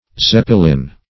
Zeppelin \Zep`pe*lin"\ (ts[e^]p`p[~e]*l[=e]"; Angl.
z[e^]p"p[-e]*l[i^]n), n.